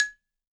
52-prc14-bala-g#4.wav